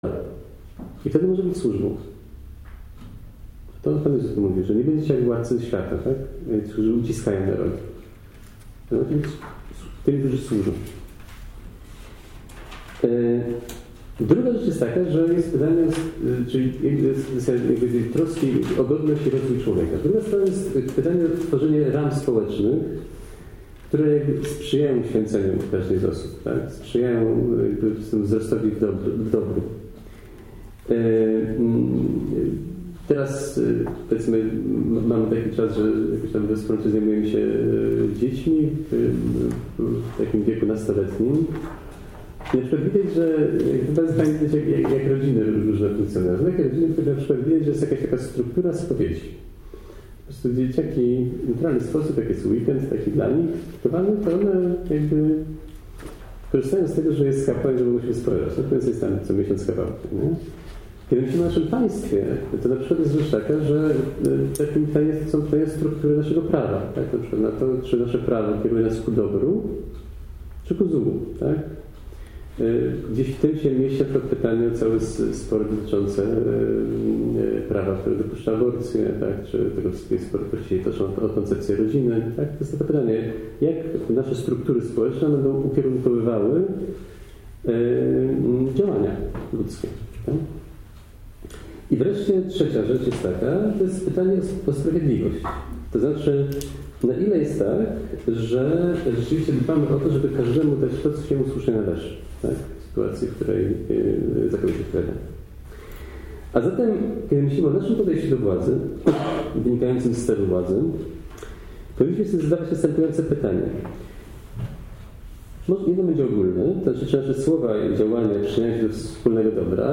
Konferencje – Zacheusz – Warszawa – 2020